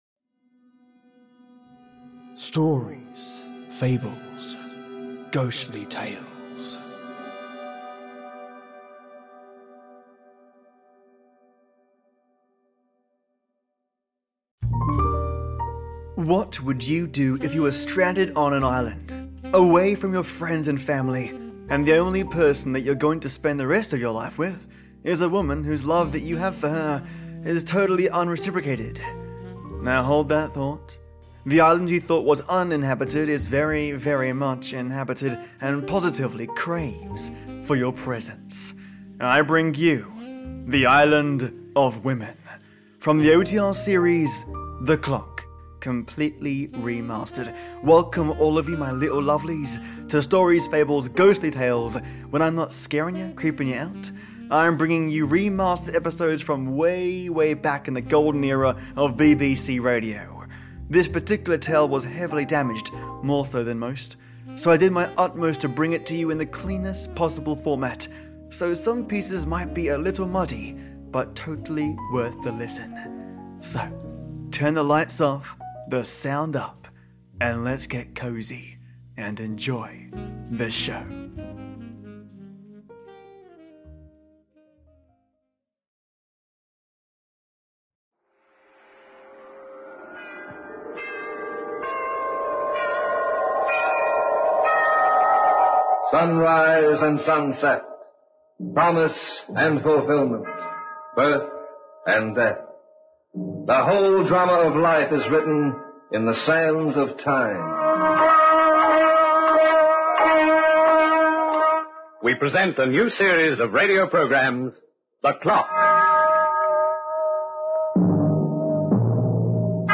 When I’m not scaring yah, creeping yah out, I’m bringing you remastered episodes from waaaaay way back in the Golden Era of BBC Radio. This particular tale was heavily damaged, more so than most, so I did my utmost to bring it to you in the cleanest possible format, so some pieces might be a little muddy, but totally worth the listen.